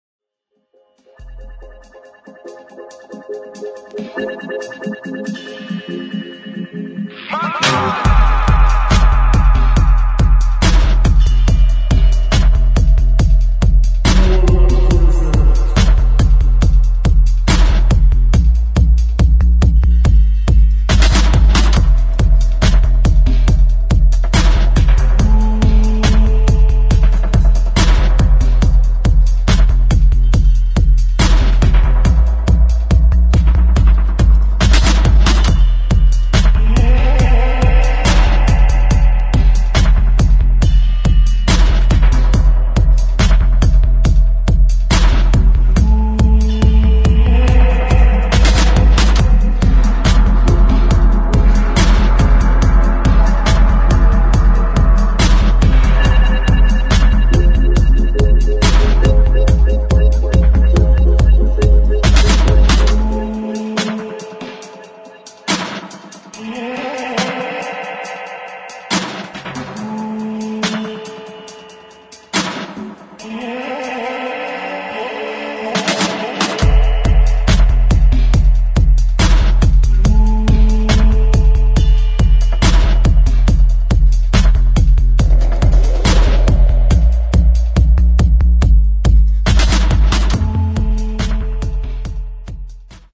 [ DRUM'N'BASS / DUBSTEP / BASS ]